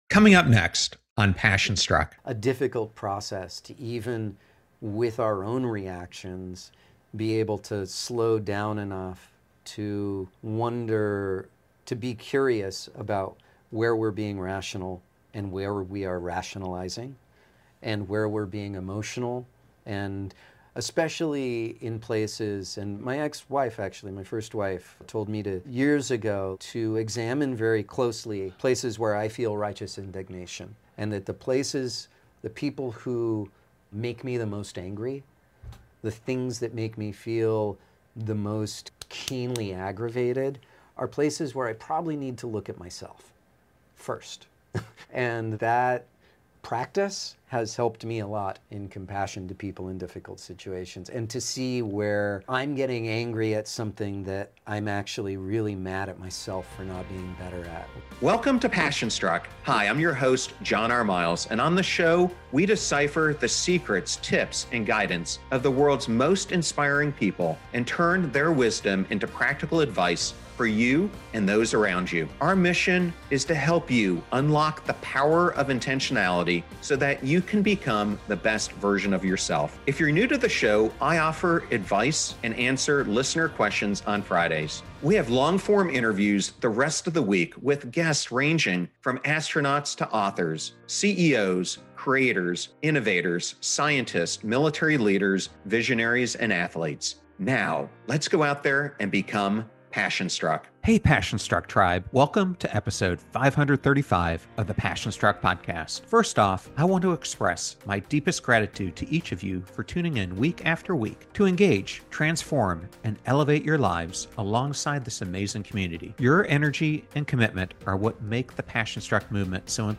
Whether you’re a longtime fan or new to his work, this conversation promises to inspire and uplift as we uncover the wisdom behind Glen’s artistry and the lessons he’s learned along the way.